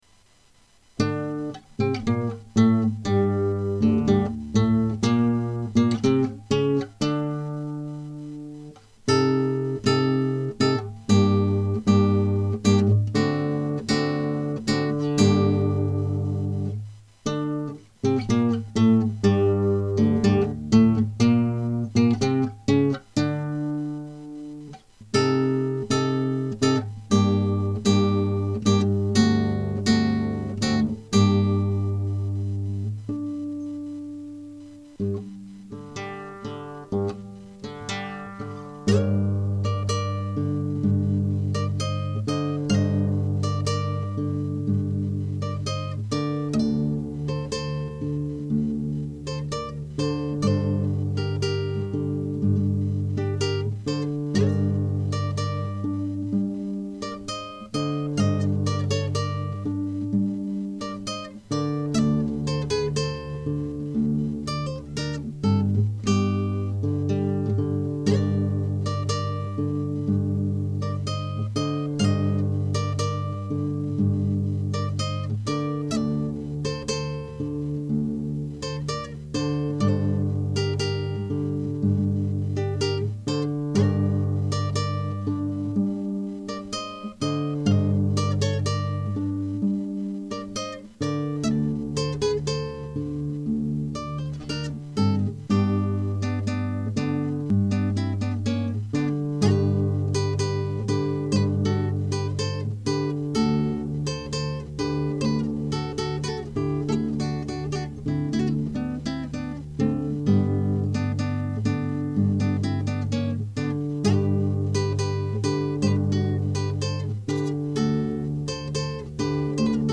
- Guitare Classique
Donc le voici avec des "couics", des "couacs", des "re-couics", et des "re-couac" ! :biggrin:
Tu t'es un petit peu précipitée à    la fin, il était si envotant ton danseur ?
Tu donnes un autre éclairage de cette pièce, on dirait deux voix qui se répondent, j'aime bien.